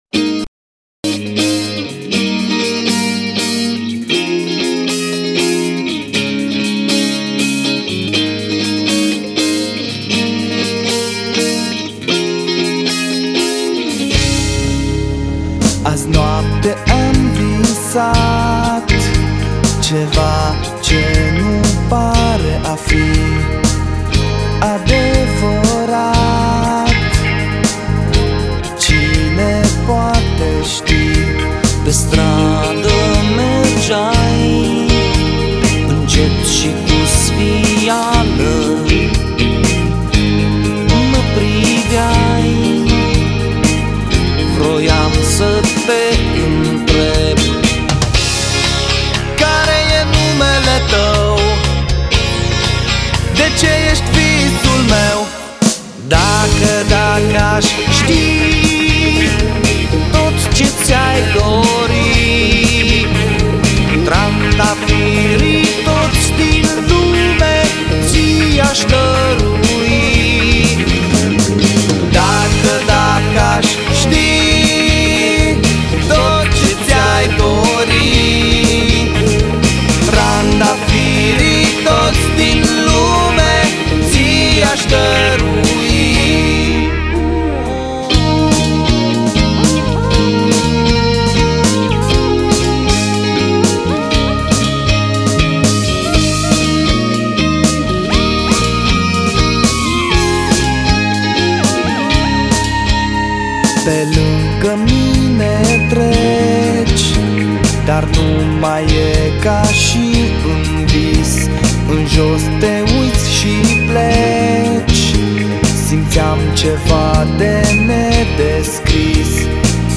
flaut, voce